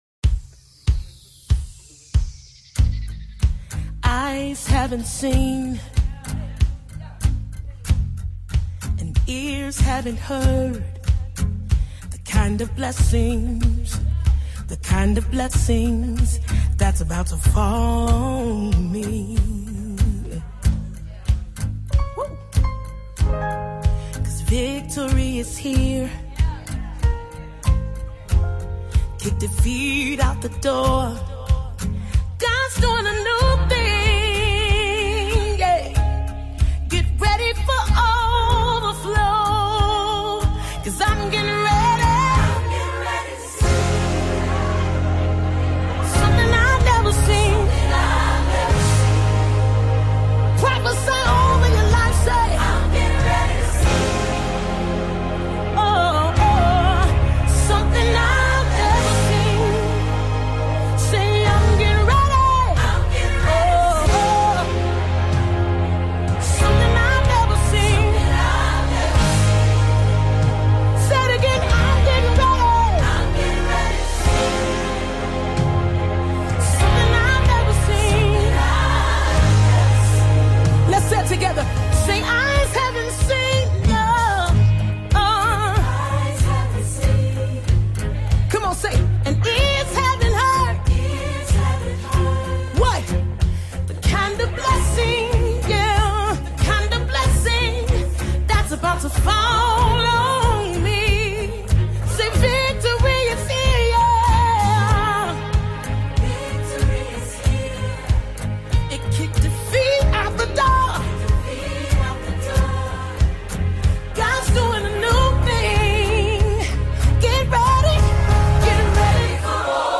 the Award Winning gospel artiste